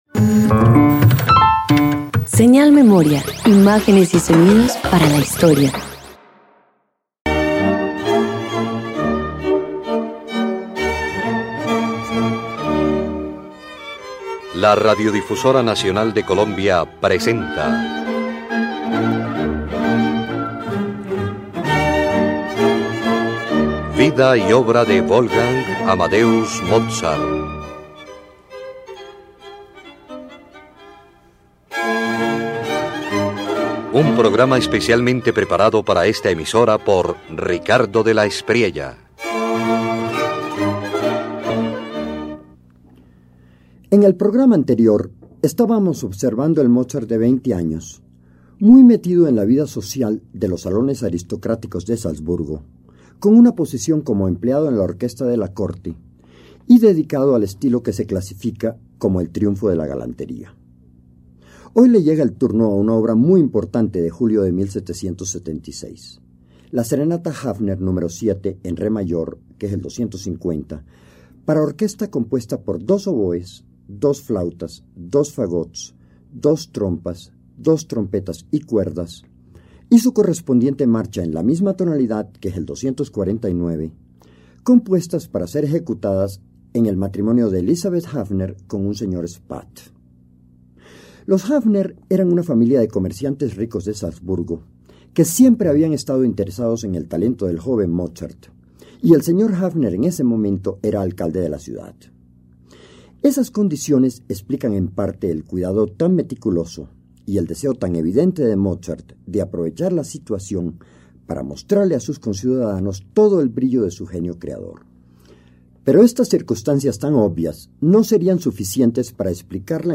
Wolfgang Amadeus Mozart compone la Serenata Hafner K250 y su marcha K249 para una boda en Salzburgo. Con brillante orquestación y aire festivo, el joven maestro combina elegancia galante y ambición sinfónica en una de sus obras más notables.